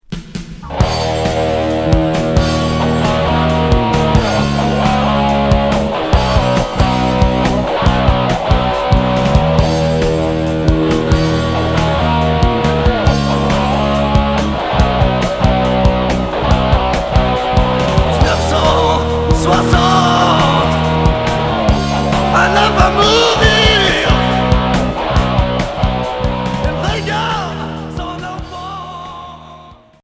Hard Unique 45t TOURS retour à l'accueil